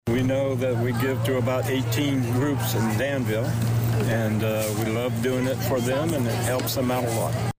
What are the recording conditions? The David S. Palmer Arena was the place to be for Thursday’s 74th Kiwanis Pancake Day in Danville.